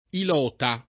vai all'elenco alfabetico delle voci ingrandisci il carattere 100% rimpicciolisci il carattere stampa invia tramite posta elettronica codividi su Facebook ilota [ il 0 ta ] (antiq. iloto [ i l 0 to ]) s. m. (stor.); pl.